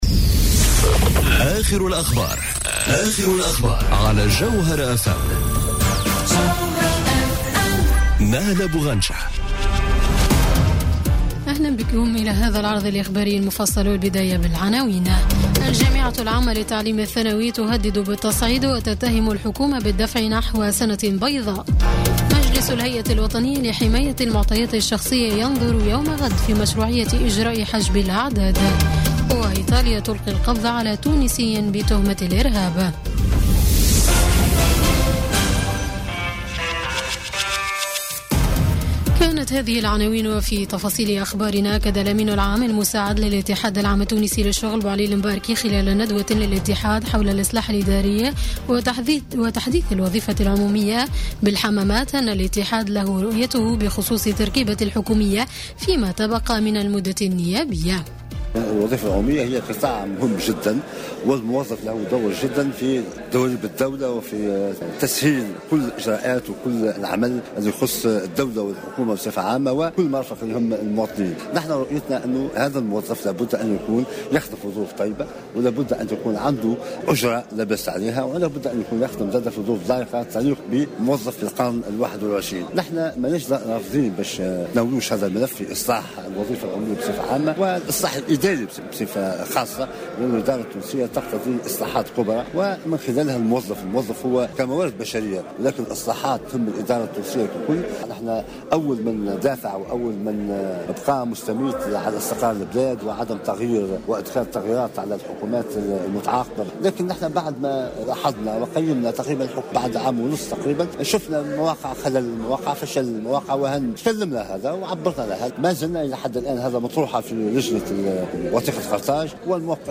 نشرة أخبار السابعة مساءً ليوم الخميس 29 مارس 2018